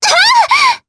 Frey-Vox_Damage_jp_03.wav